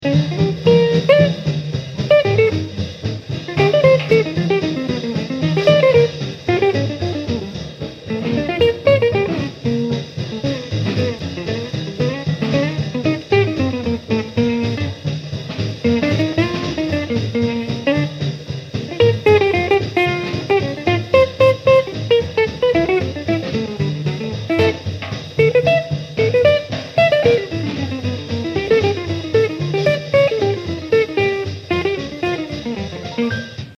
Tonalité du morceau : Bb